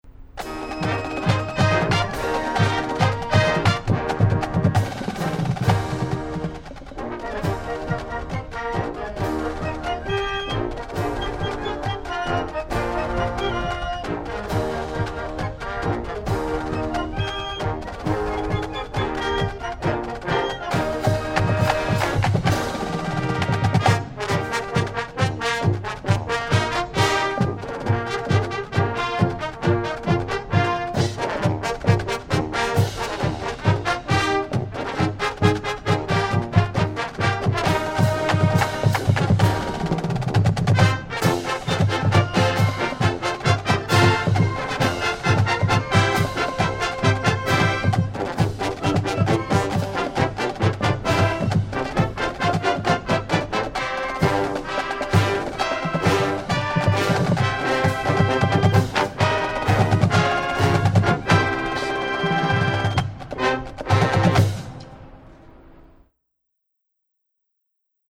Marching Band
The Lamar University marching band, titled The Showcase of Southeast Texas, performs at all home football games and select away games.